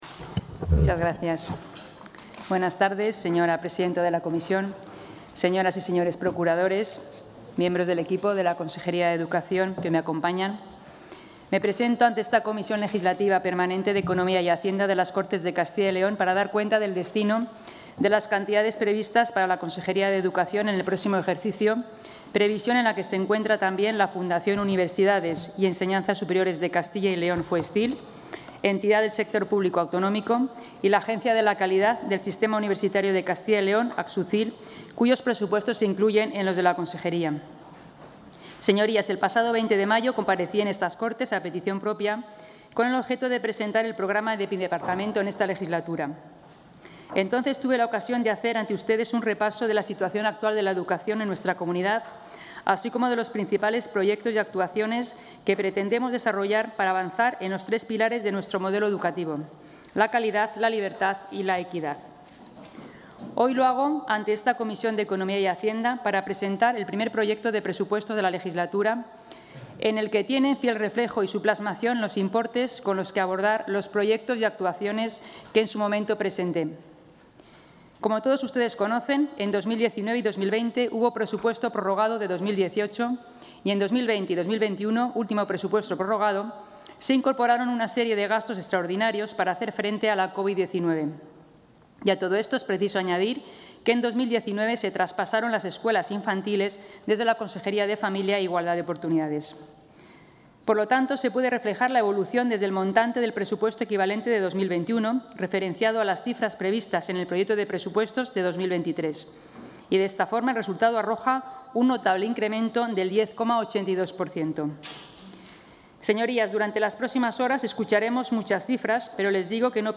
Intervención de la consejera.
La Junta de Castilla y León ha presentado esta tarde ante la Comisión de Economía y Hacienda de las Cortes autonómicas el presupuesto de la Consejería de Educación más alto de su historia.